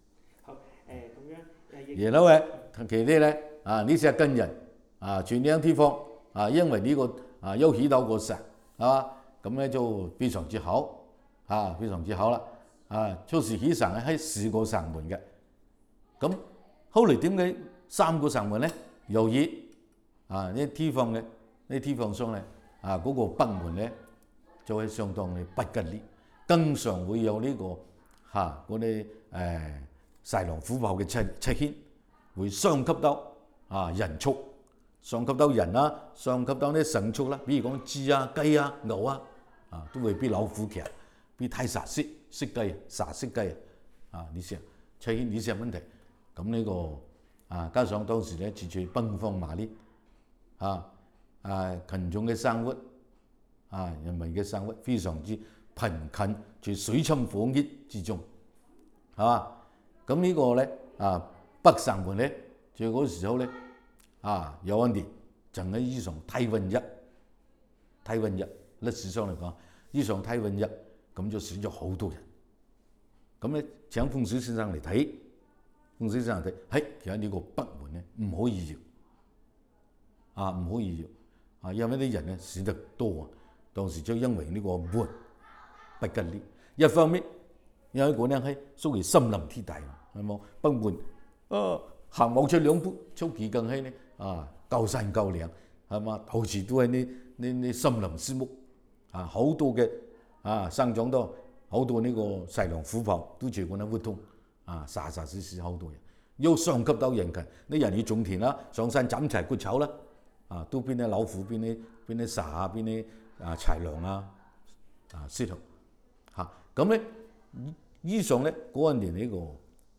Male, 63
digital wav file recorded at 44.1 kHz/16 bit on Zoom H2 solid state recorder
Dapeng dialect in Shenzhen, China